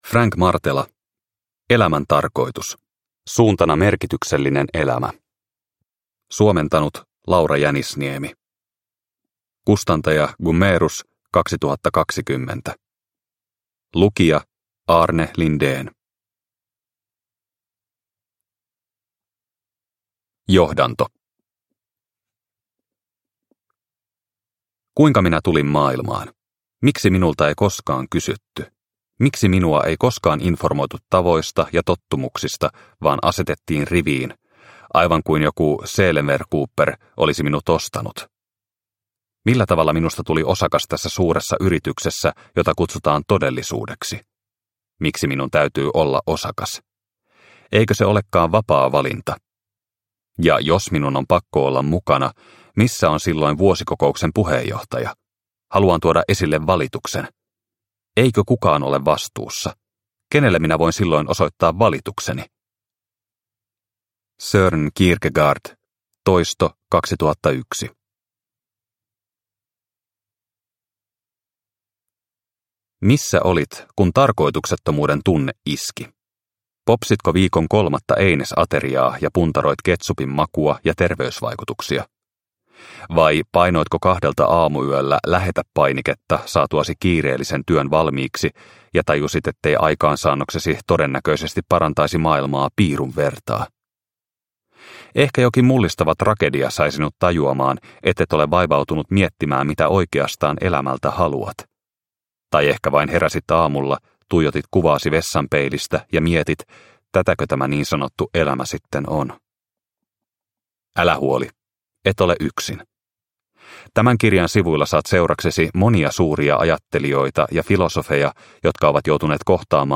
Elämän tarkoitus – Ljudbok – Laddas ner